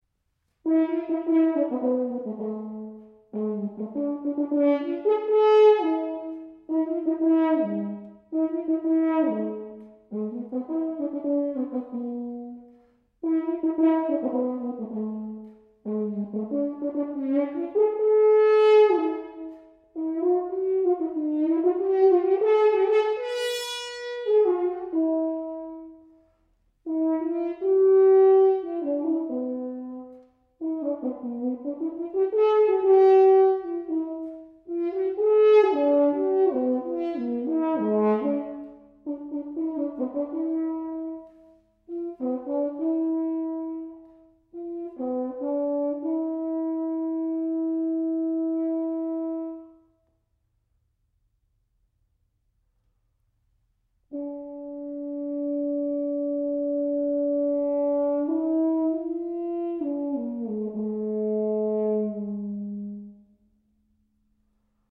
UK based natural hornist